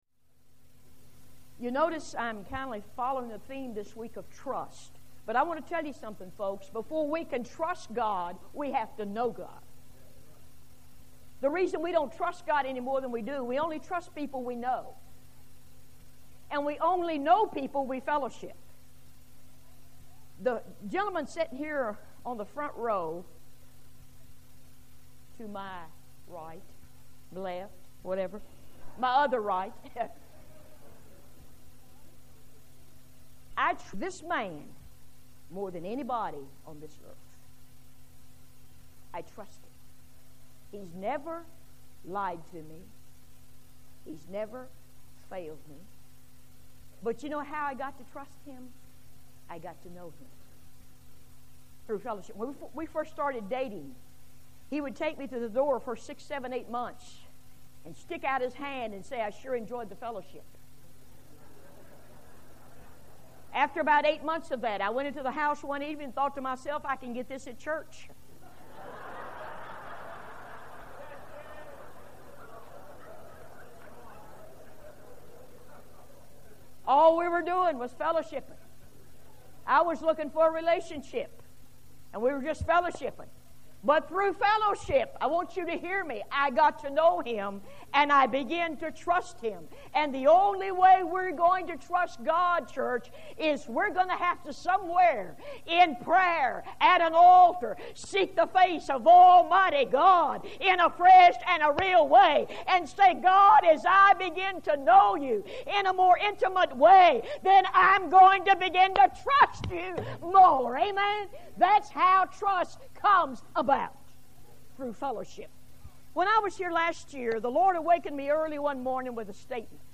Women Preachers